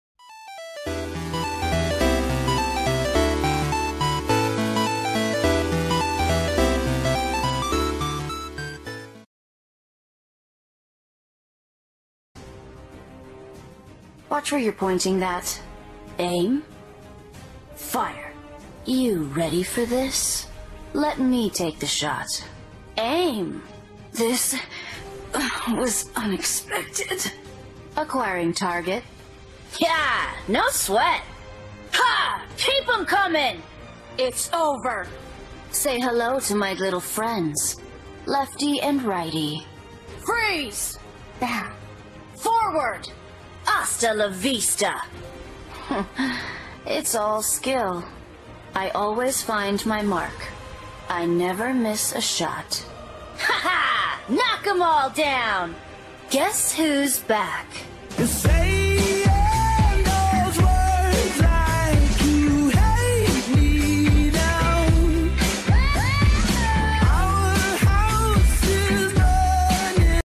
Nhấn vào text để nghe giọng nói của Violet.